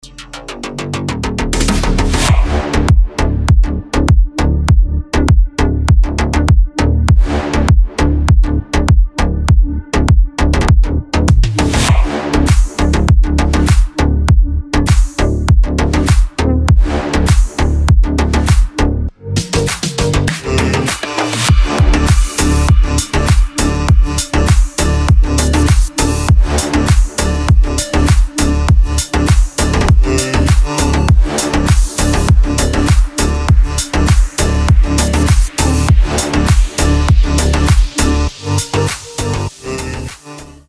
Танцевальные рингтоны
Рингтоны техно
Bass house , G-house , Мощные басы